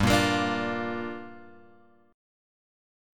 Gm7 chord {3 1 x 3 3 1} chord